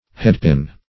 headpin \head"pin`\